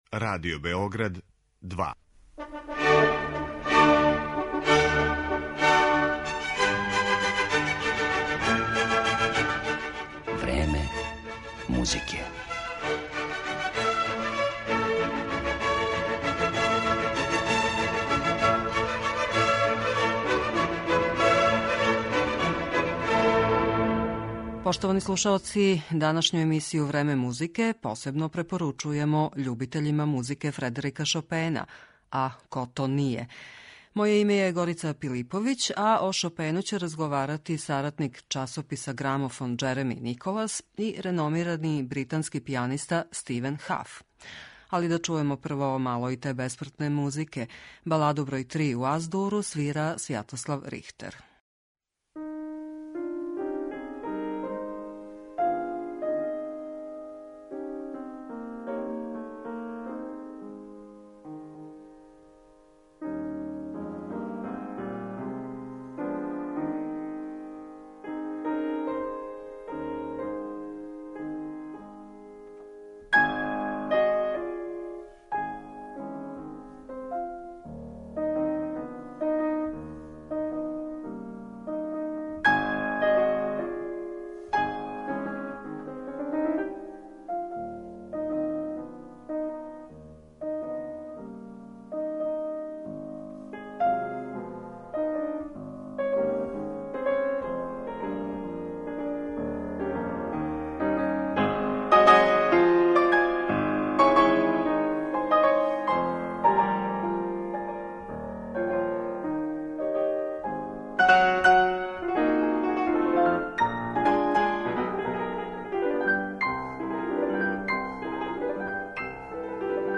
РАЗГОВОР О ШОПЕНУ